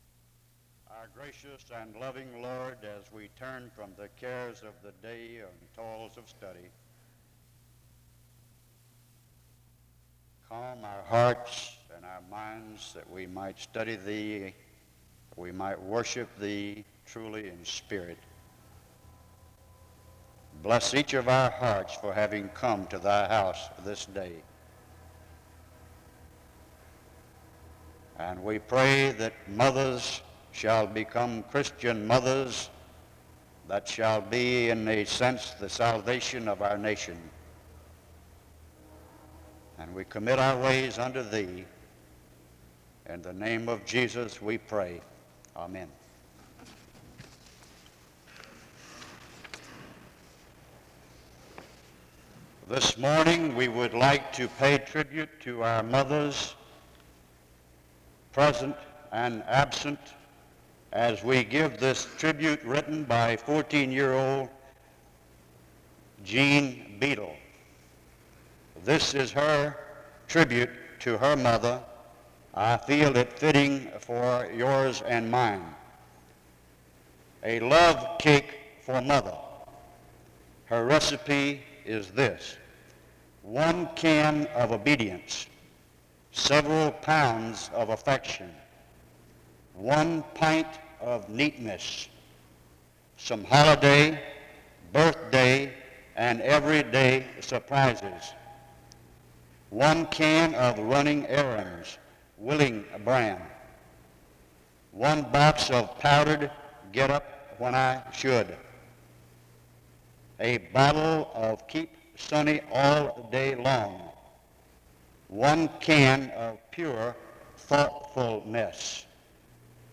Download .mp3 Description The service begins with a word of prayer from 0:00-0:45. A tribute to all the mothers in attendance is given from 0:53-2:15.
SEBTS Chapel and Special Event Recordings SEBTS Chapel and Special Event Recordings